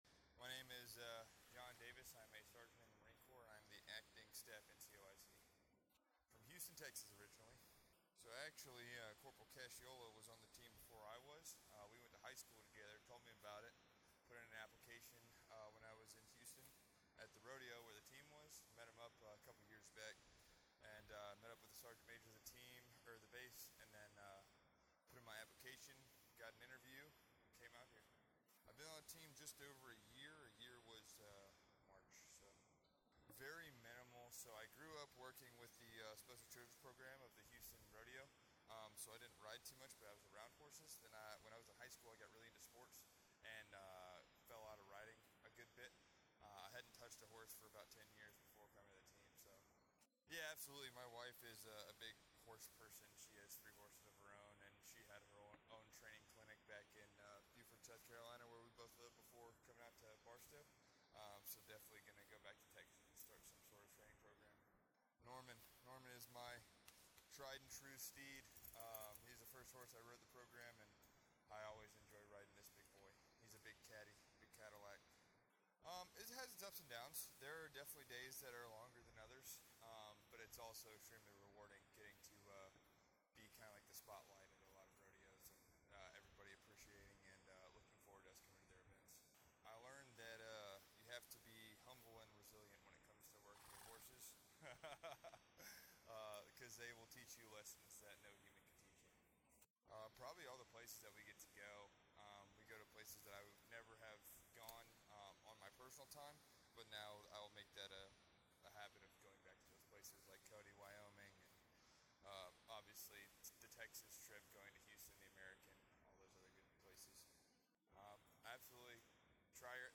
Audio Interview
conducts an interview at Iron Hill Ranch in Union, Missouri on May 13, 2024. MCG conducted a cross-country journey from Marine Corps Logistics Base Barstow, California to the National Capital Region for the first time in over a decade to participate in Preakness, the National Memorial Day Parade and a series of additional events designed to uphold the Marine Corps’ prominence, promote the Marine Corps’ only Mounted Color Guard asset and support recruiting nationwide.